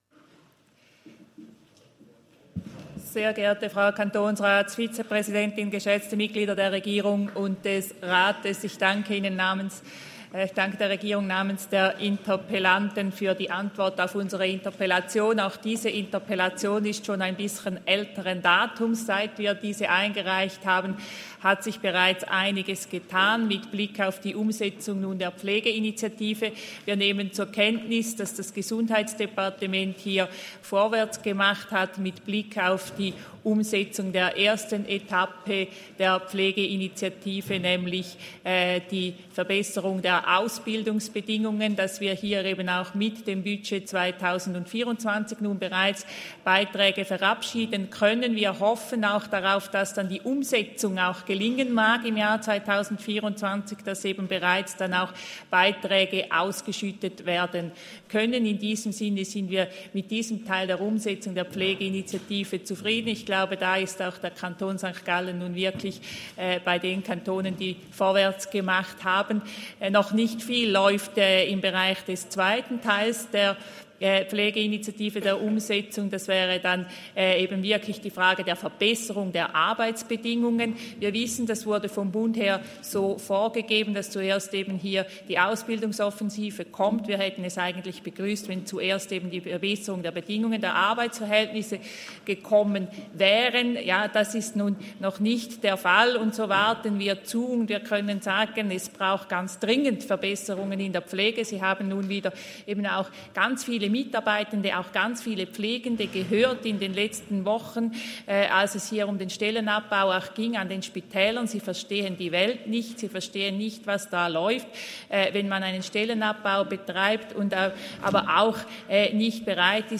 Session des Kantonsrates vom 27. bis 29. November 2023, Wintersession